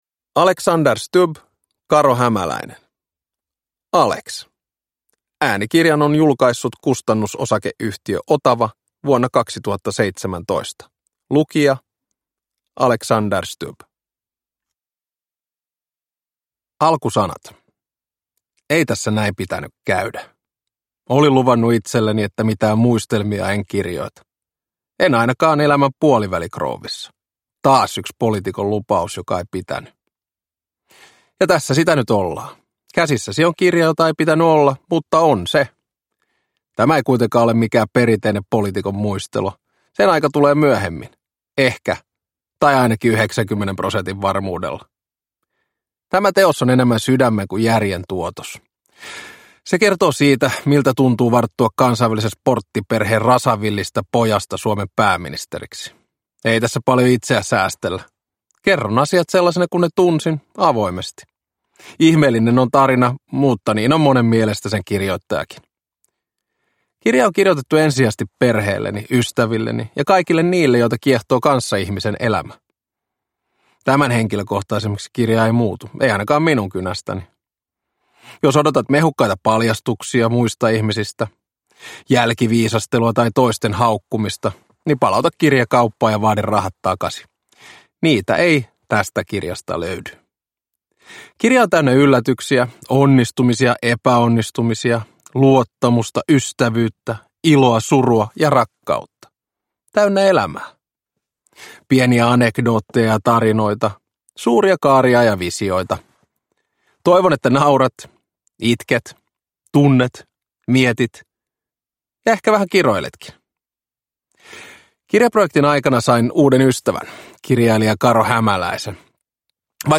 Alex – Ljudbok – Laddas ner
Uppläsare: Alexander Stubb